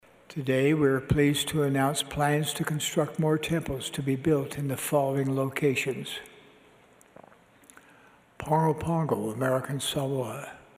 President Nelson made the  announcement  yesterday at the conclusion of the 189th annual Church conference in Salt Lake City.